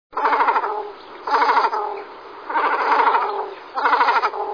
Kormoran czarny - Phalacrocorax carbo
głosy